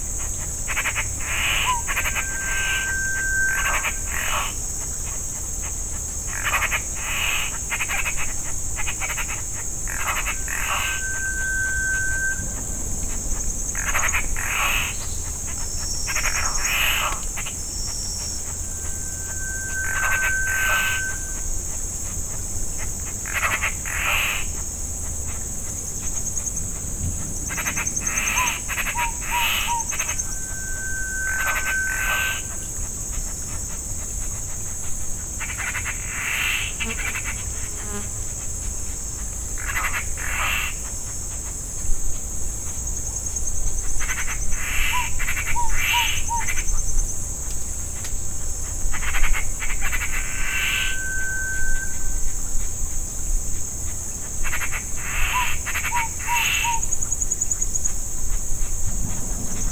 At this spot we had both Fluffy-backed Tit-Babbler and Malaysian Rail-Babbler. We made a 1 minute sound recording of both species together.
this the full wav recording (96kHz/32 bit stereo; wav file, 22 MB). The rail-babbler is the whistling note on one pitch, the other two sounds are from the tit-babbler.
We were very lucky with the rail-babbler, so closeby!